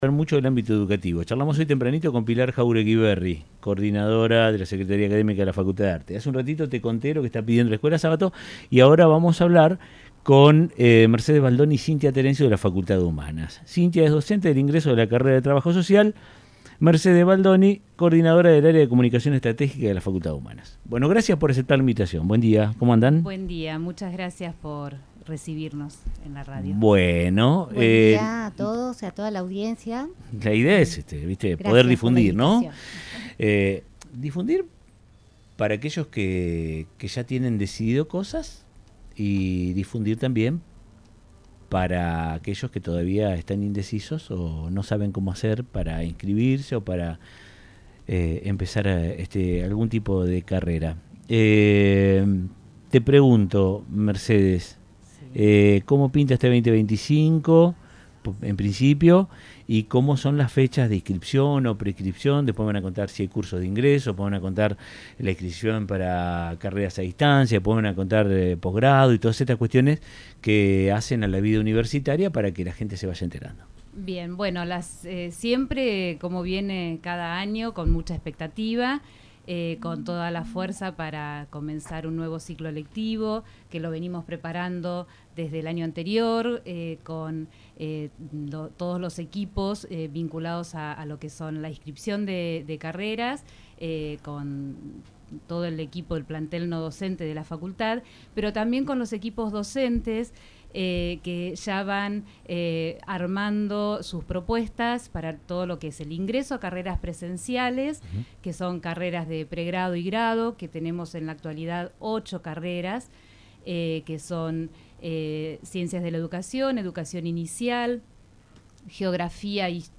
En charla con RadioTandil, representantes de la FCH conversaron sobre la inscripción a carreras para el ciclo lectivo 2025.